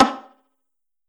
select-difficulty.wav